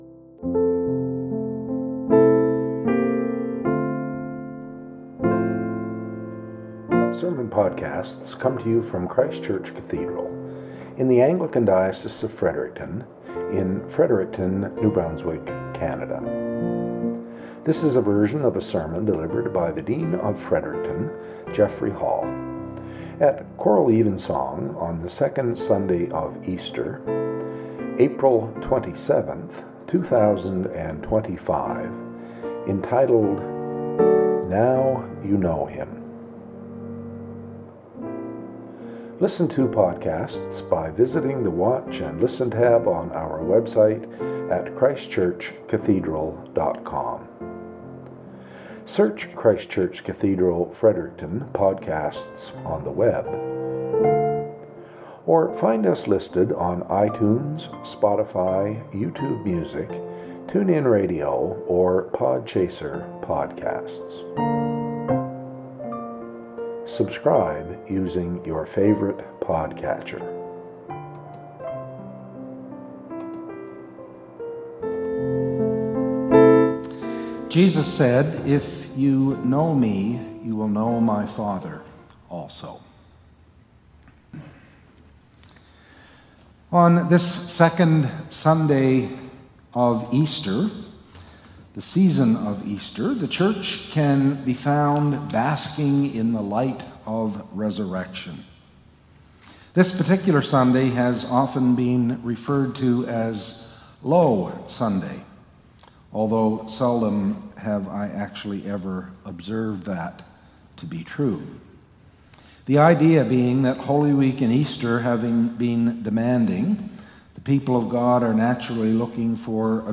Podcast from Christ Church Cathedral Fredericton
SERMON - "Now You Know Him"